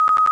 notify.ogg